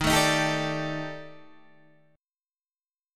EbM7b5 chord